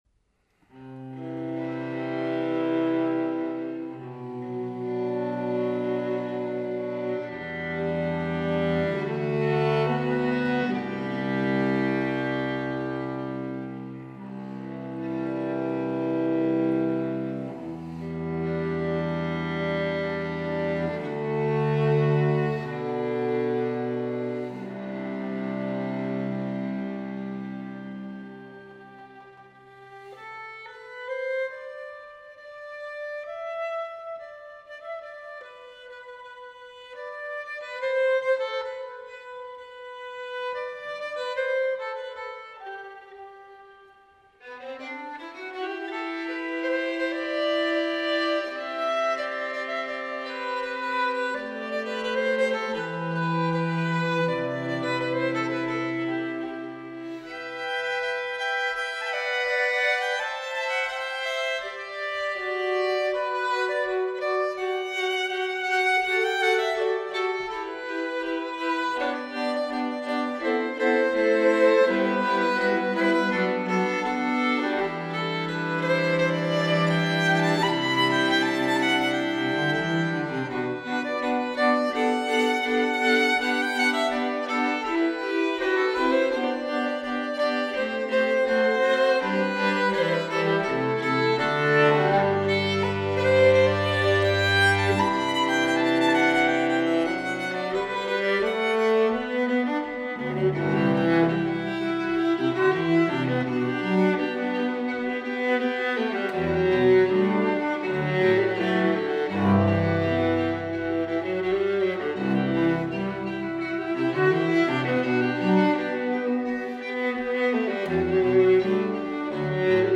vibrant, and sometimes wistful, folk music